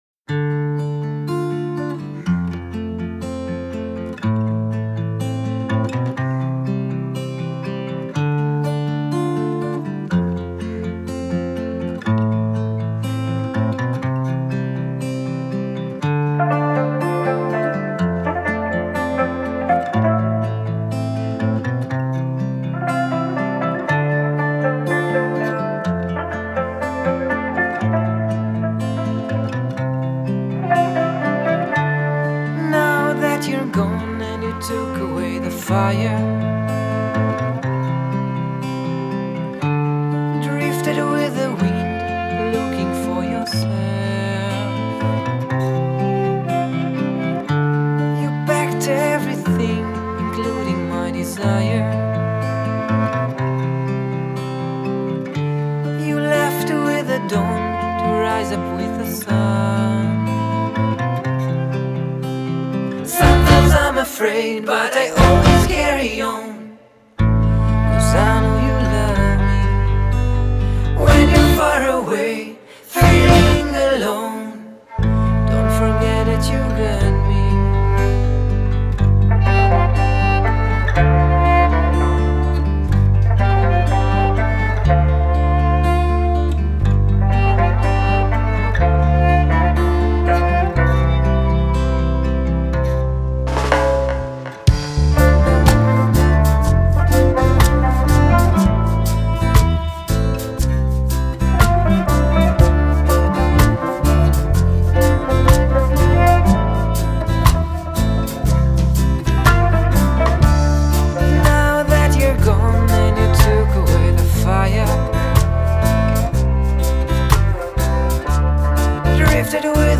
Electro – Ethnic – Reggae group
Genre: World